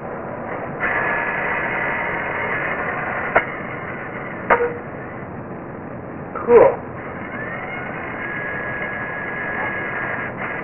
Mars Microphone: Test Sound Data (Robot Arm, 5 kHz)